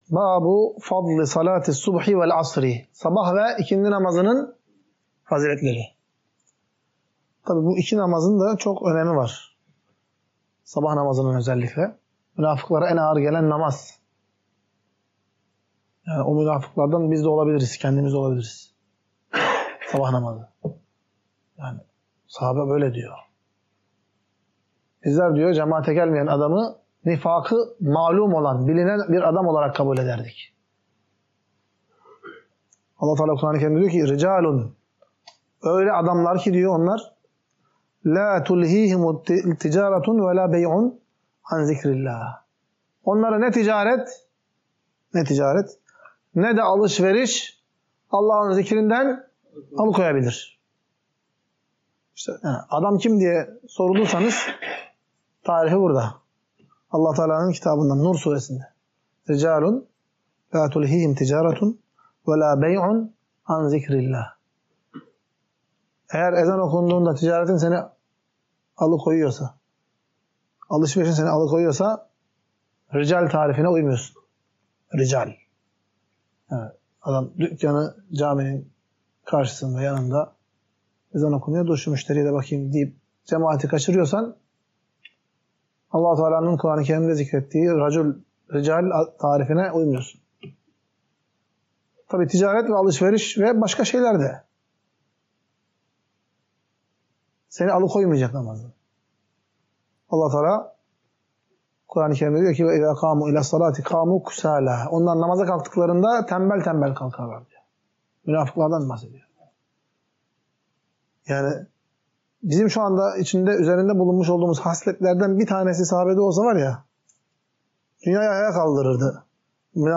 Ders - 9. BÖLÜM | SABAH VE İKİNDİ NAMAZININ FAZİLETİ